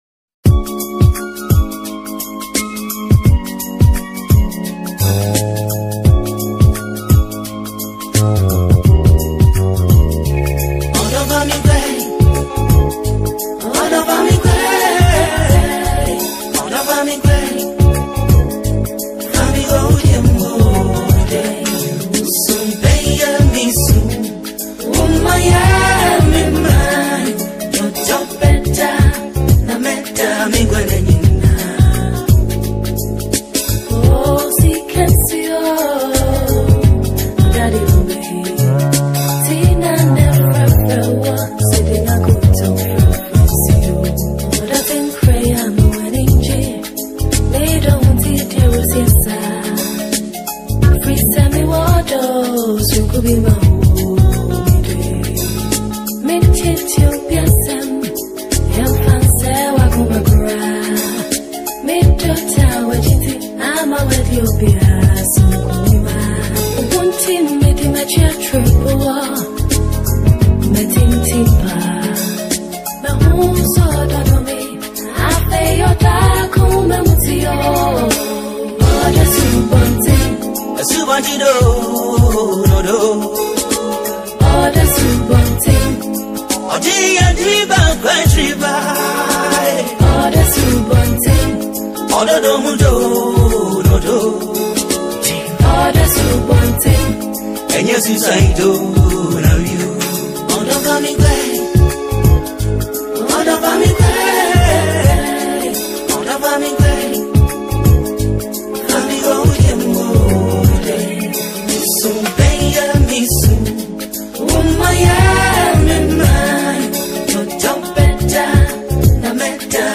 The song features rich instrumentals, poetic lyrics
• A classic blend of traditional and modern highlife music.